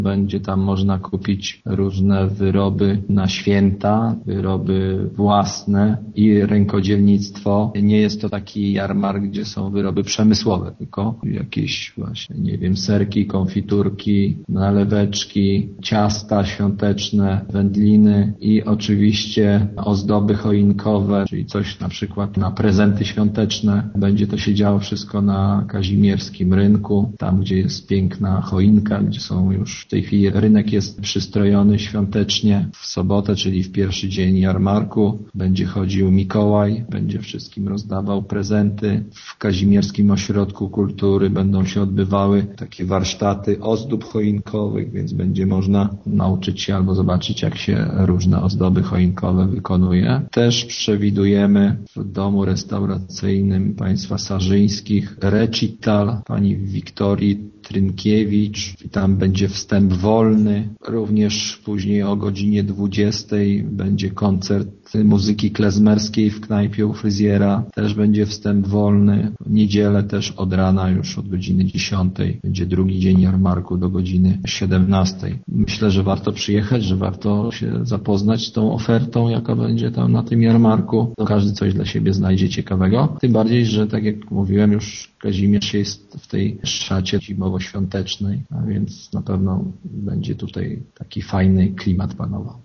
„Jarmark będzie nawiązywać do tradycyjnych kazimierskich jarmarków, a na straganach będzie można znaleźć wszystko co może się przydać na święta” - zaprasza burmistrz Kazimierza Grzegorz Dunia: